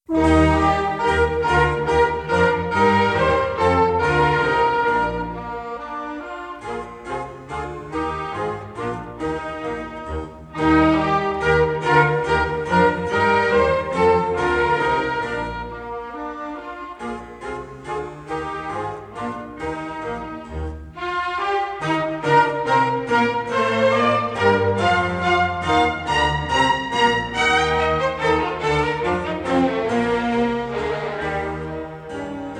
in E flat major